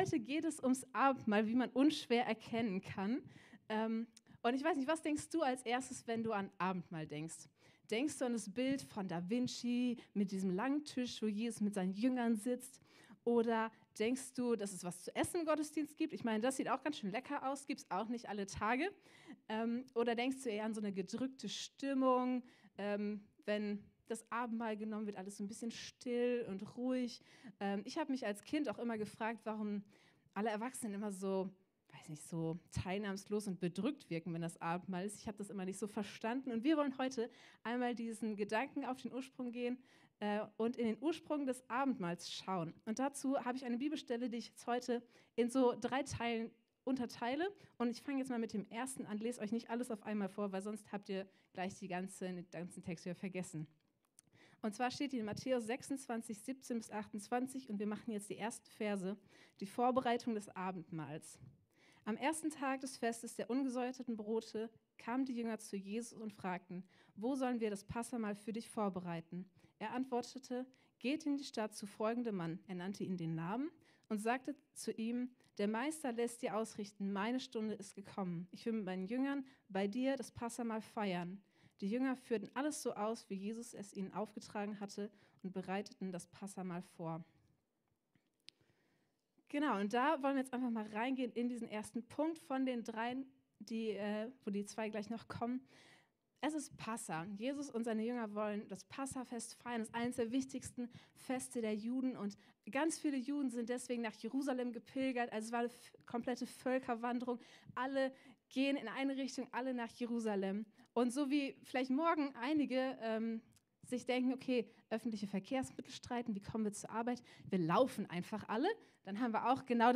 Unsere Predigt vom 26.03.23 Predigtserie: Die Passion Teil 4 Folge direkt herunterladen